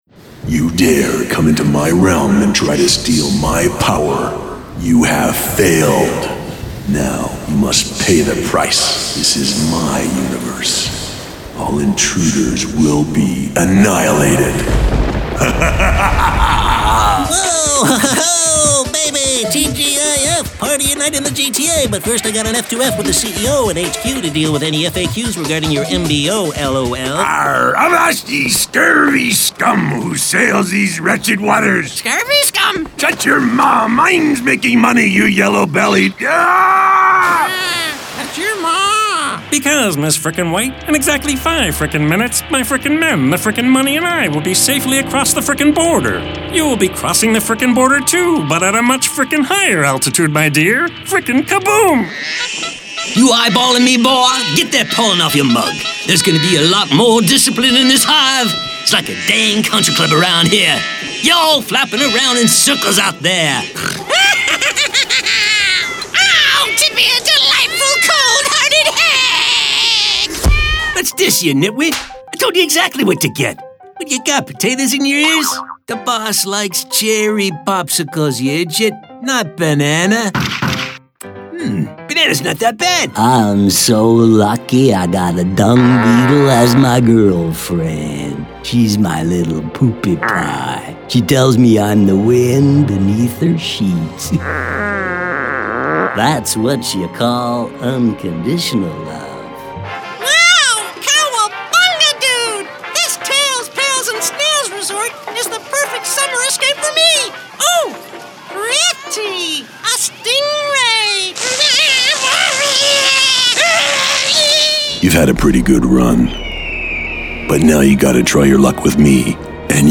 Masculino
Inglês - América do Norte
Medical Narration Demo
-High quality work from a professional home recording studio.